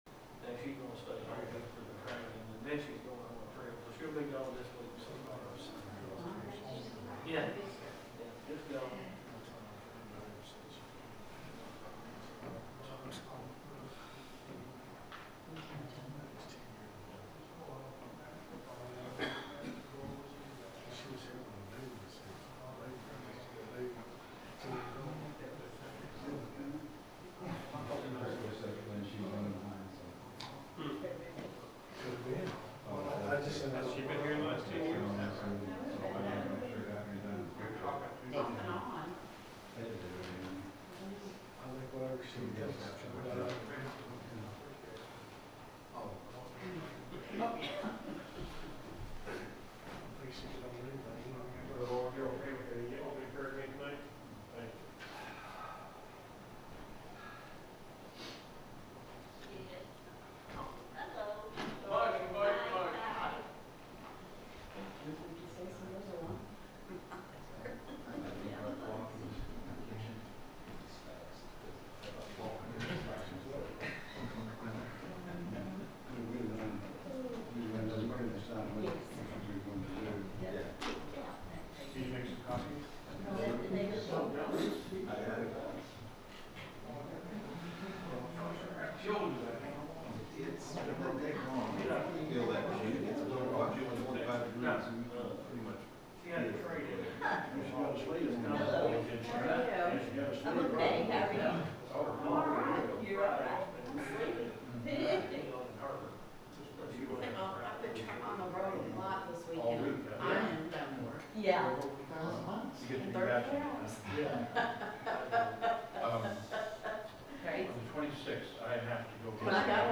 The sermon is from our live stream on 1/11/2026